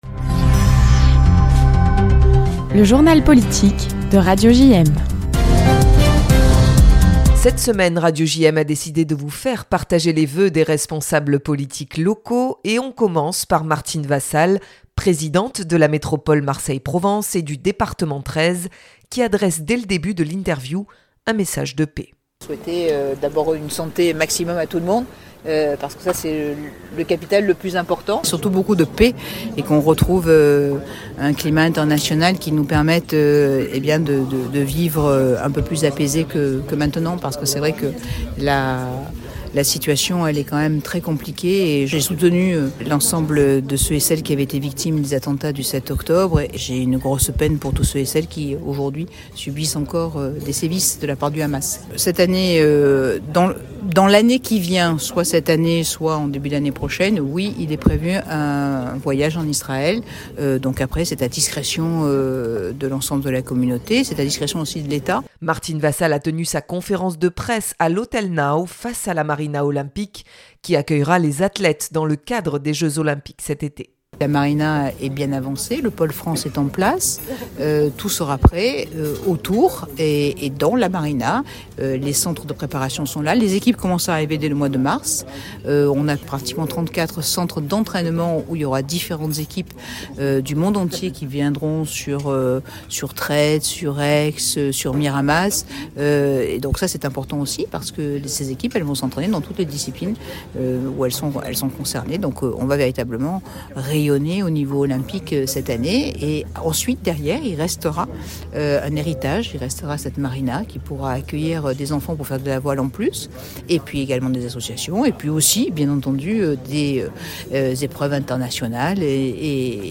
Retrouvez les vœux à la presse de nos élus locaux : Martine Vassal, Présidente de la Métropole Aix-Marseille et Benoit Payan, Maire de Marseille.